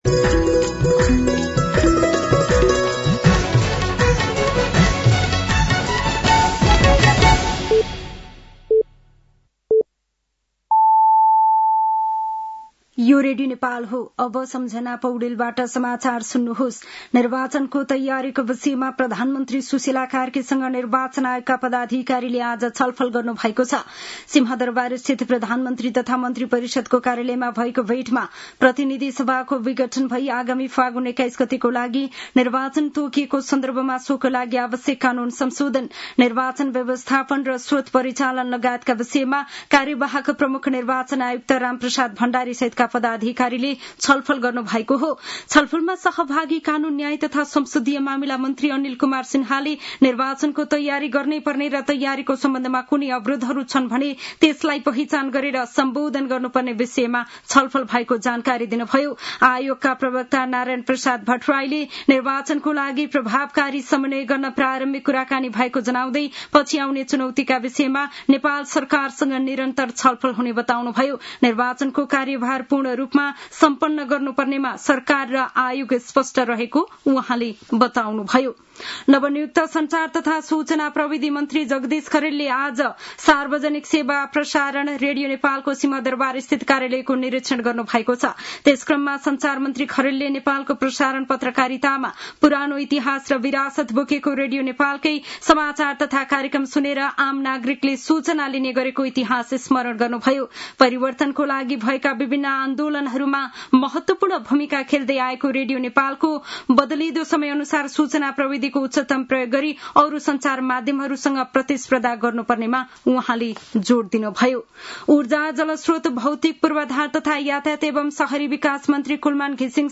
साँझ ५ बजेको नेपाली समाचार : ७ असोज , २०८२